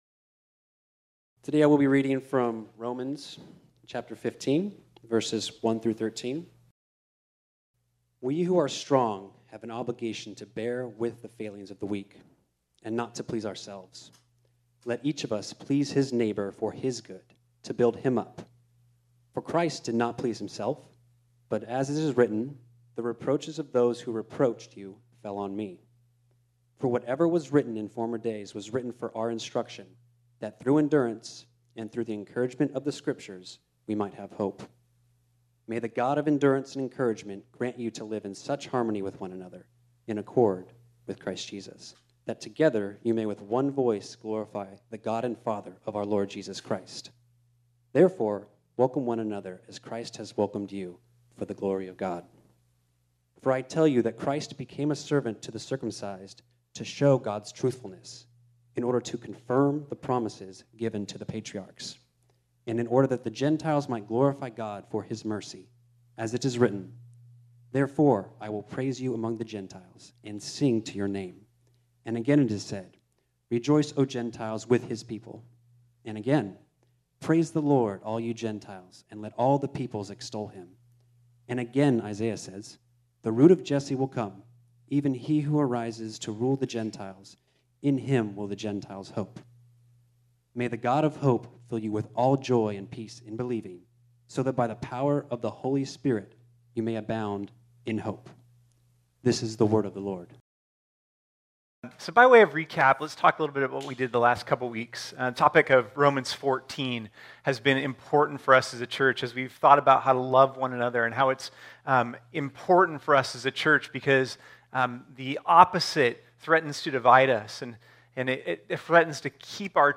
This sermon was originally preached on Sunday, August 15, 2021.